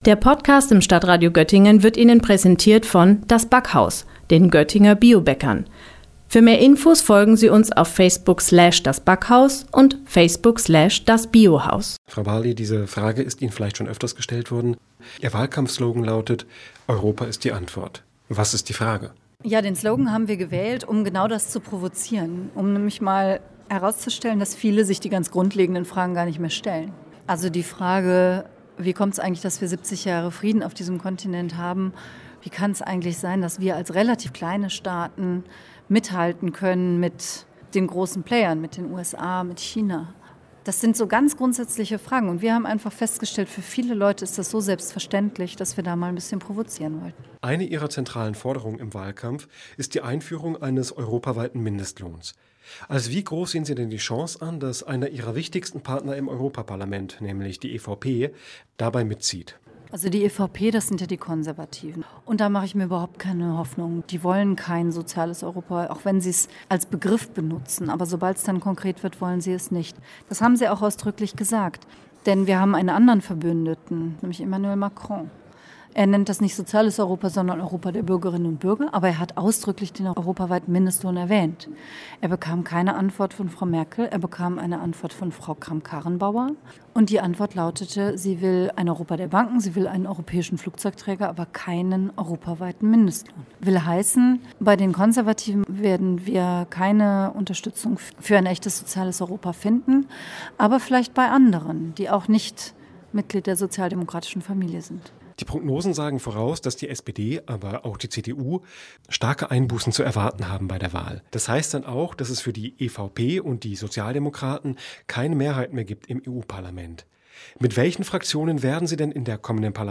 Beiträge > Europawahl in Göttingen – SPD-Spitzenkandidatin Katharina Barley im Interview - StadtRadio Göttingen
Am Montag war Barley im Startraum Göttingen zu Gast.